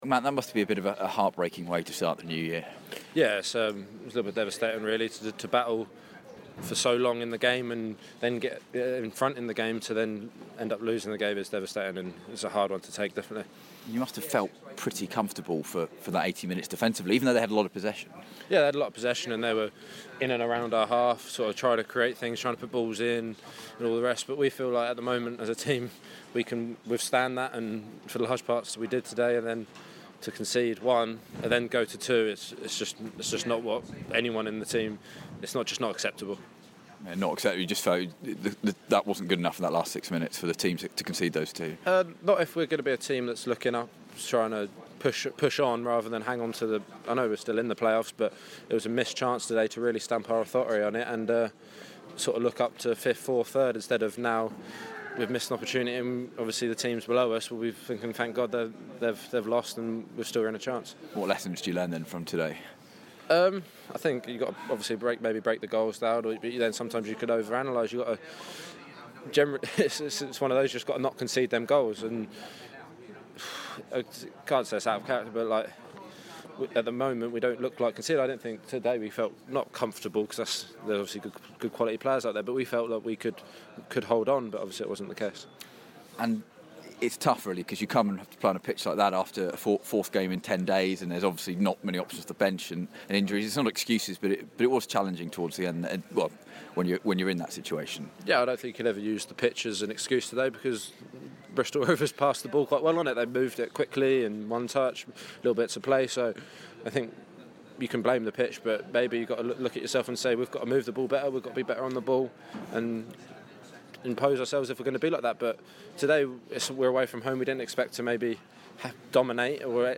REACTION: Defender Matt Clarke speaks